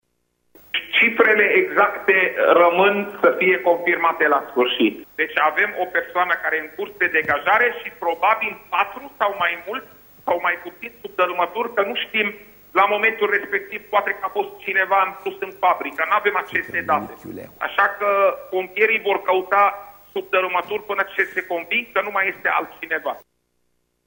De altfel, doctorul Arafat spune că au fost mobilizate echipaje de intervenţie din judeţele limitrofe şi că spitalele din judeţele Braşov şi Covasna sunt pregătite să primească răniţi.